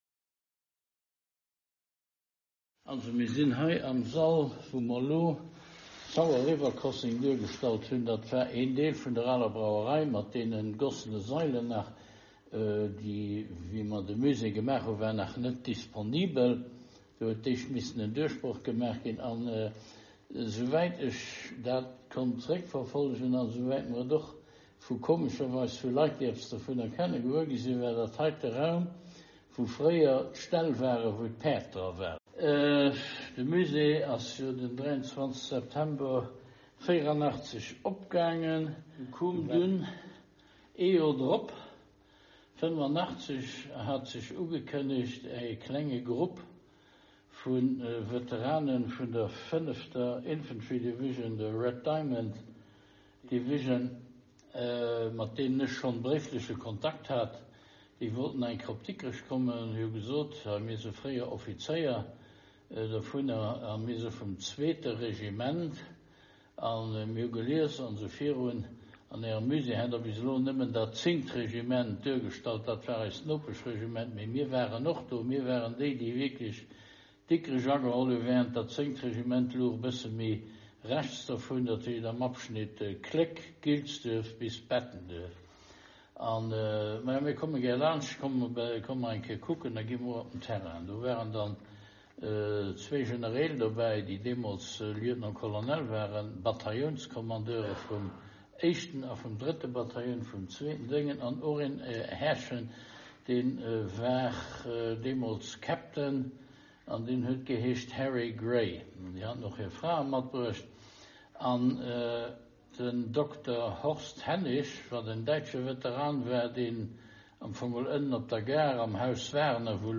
Interview on Sauer River Crossing January 18, 1945